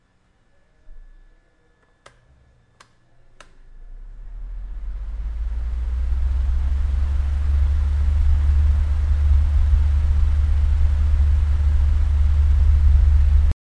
描述：abanico encendido se apaga